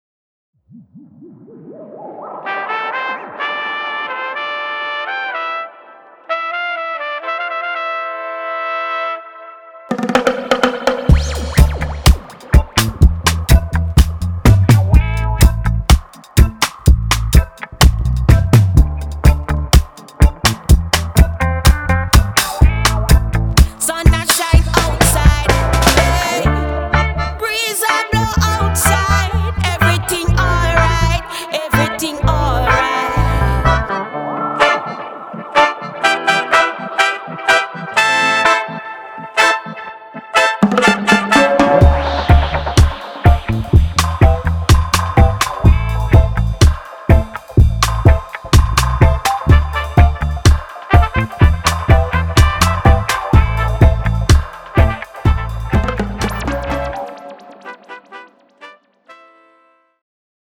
powerful, metallic vocals, delivered with bold tenacity.